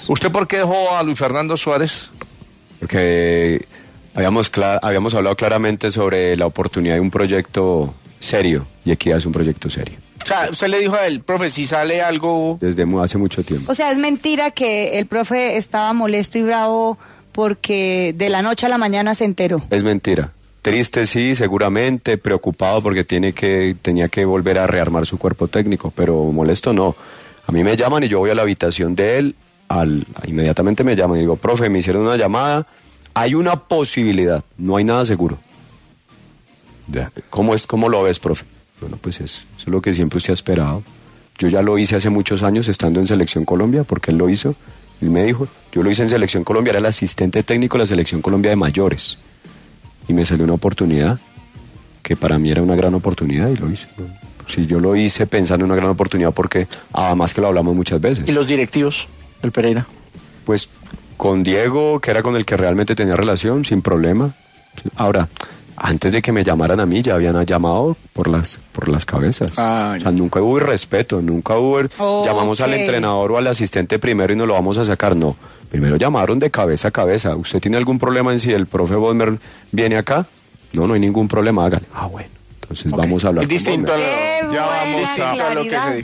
pasó por los micrófonos de Caracol Radio, en ‘La Polémica’ y contó detalles acerca de su salida del Deportivo Pereira